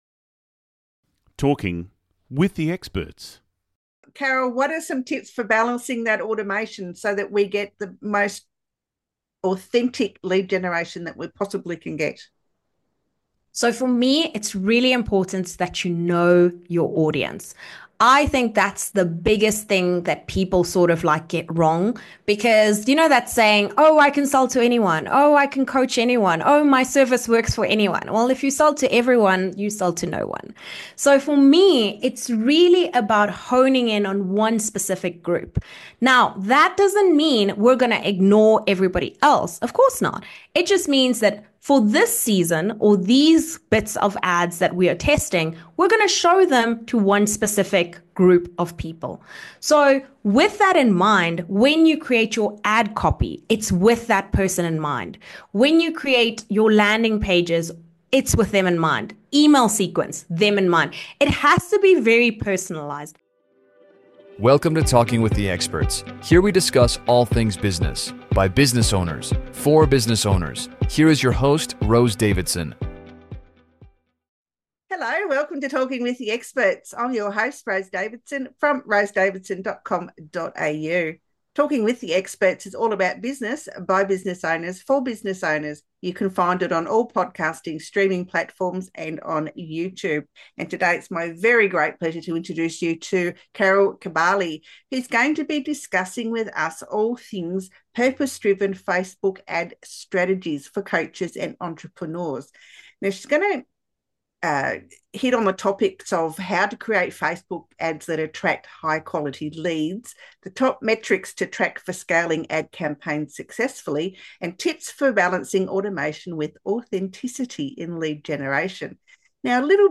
A significant portion of the conversation addresses the common fears associated with Facebook advertising, such as the apprehension of overwhelming complexities and the potential for burnout.